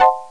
Strings# Sound Effect
strings.mp3